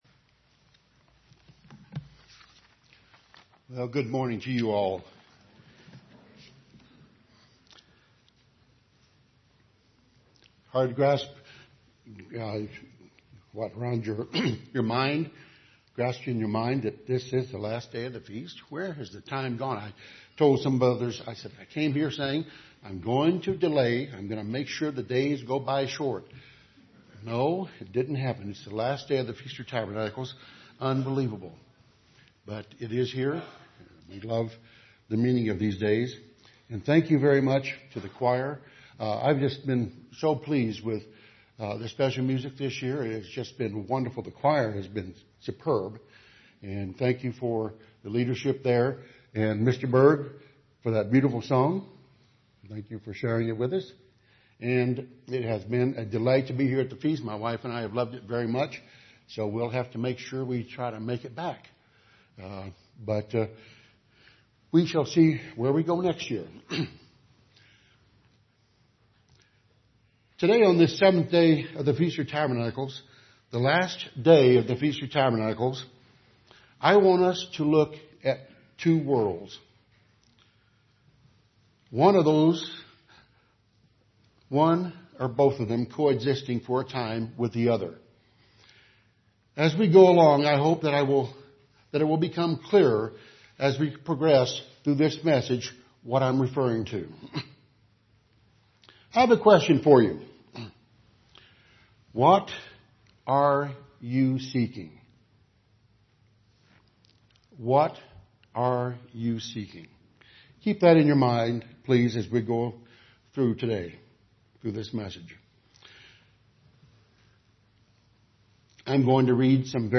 This sermon was given at the Steamboat Springs, Colorado 2015 Feast site.